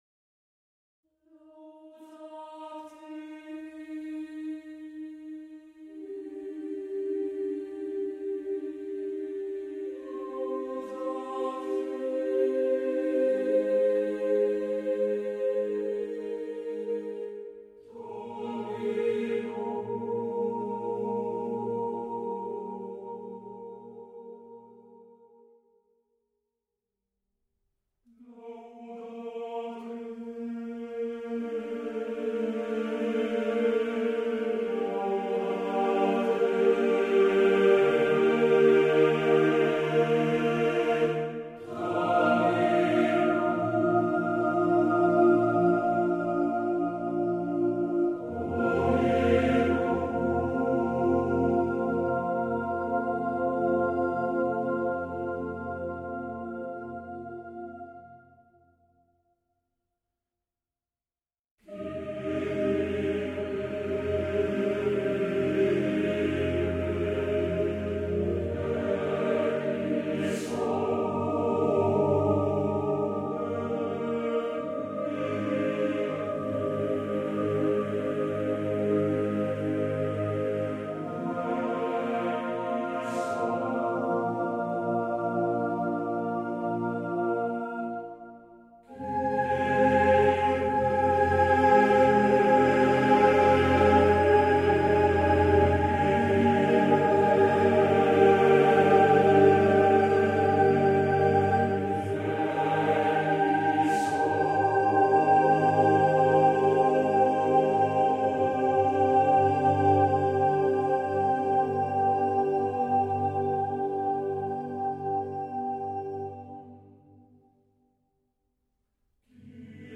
for choir